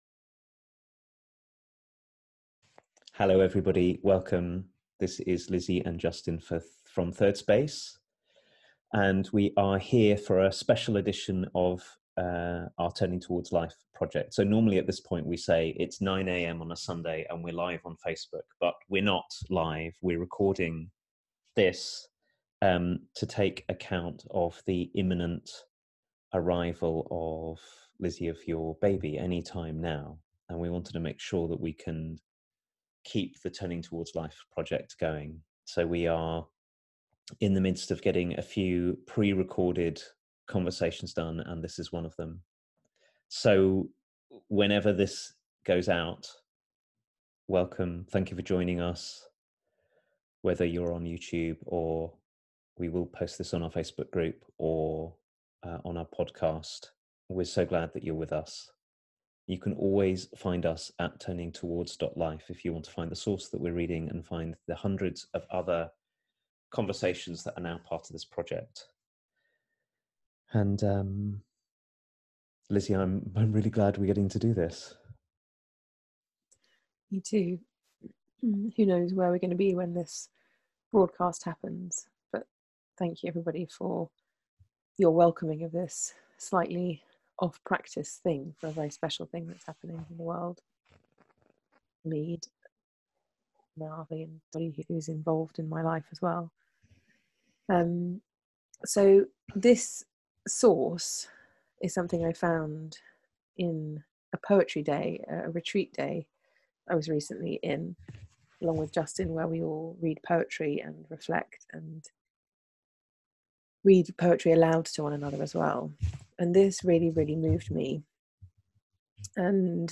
A conversation about our power to either recognise or smother the beauty in people